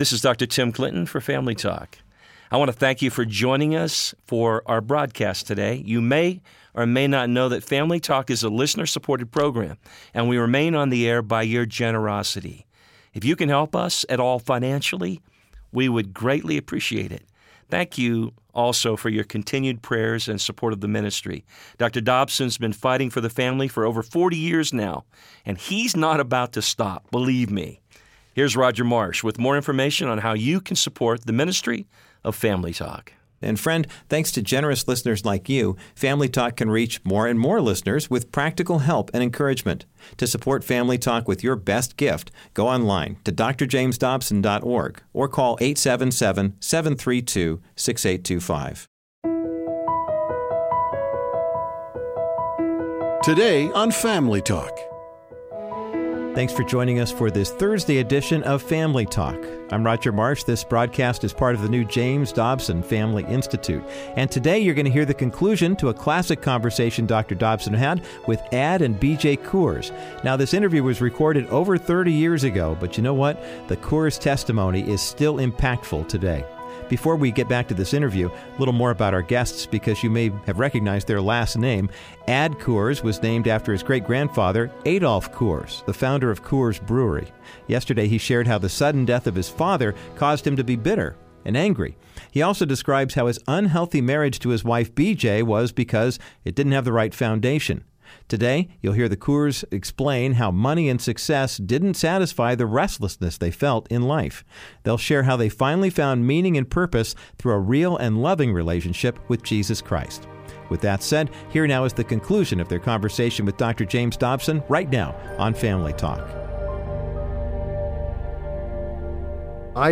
Dont miss this timeless interview on this edition of Dr. James Dobsons Family Talk.